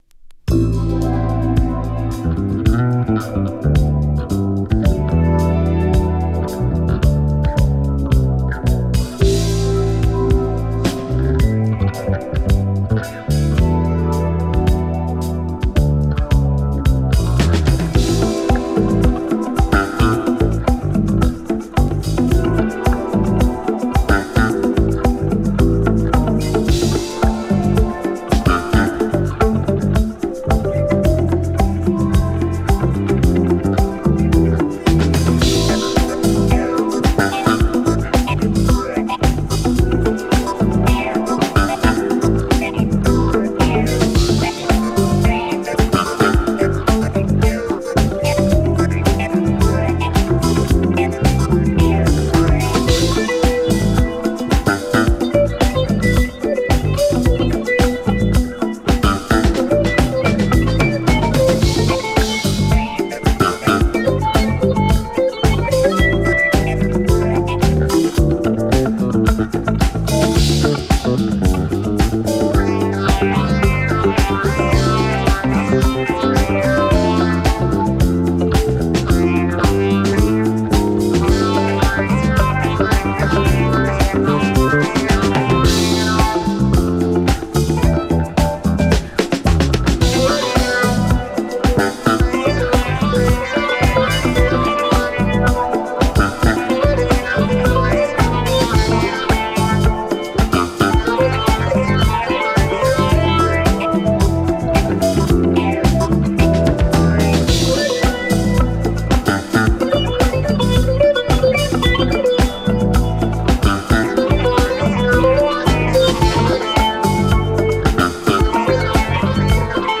生楽器をフィーチャーした70年代スムースジャズ・テイスト!